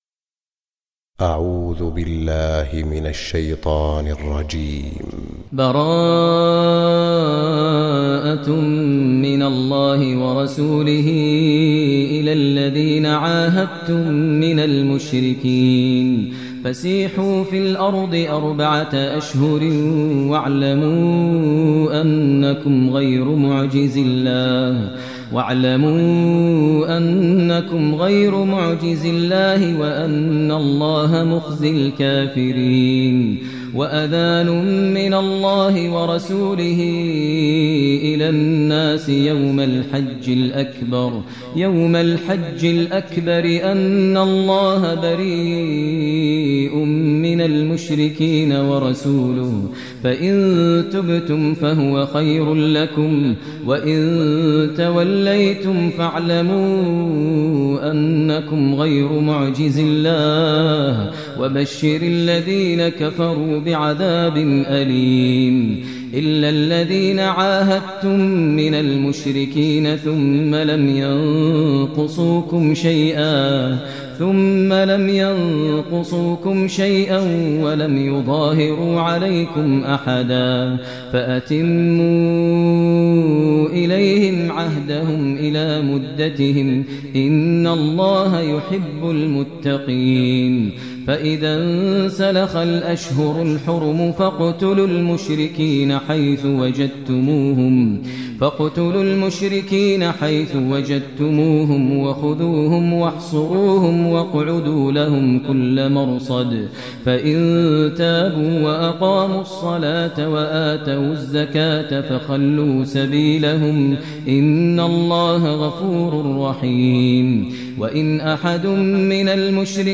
Surah Taubah Recitation by Maher al Mueaqly
Surah Taubah, listen online mp3 tilawat / recitation in the voice of Sheikh Maher al Mueaqly.